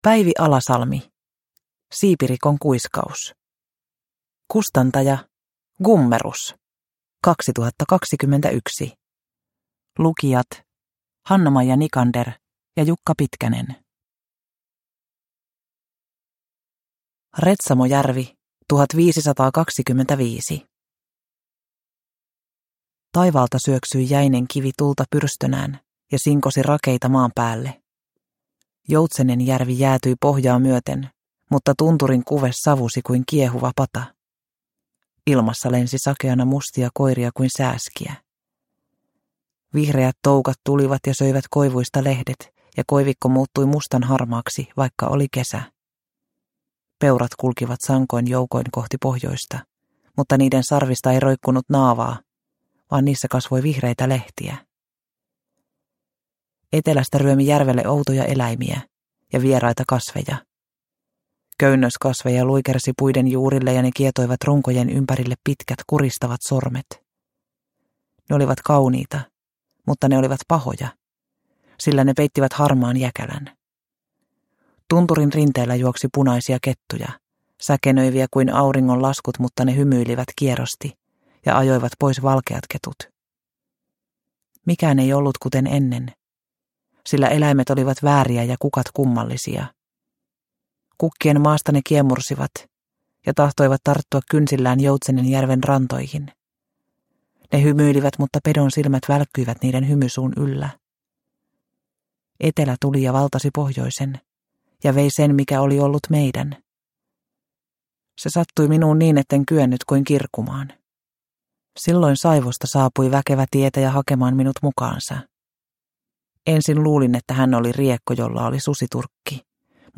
Siipirikon kuiskaus – Ljudbok – Laddas ner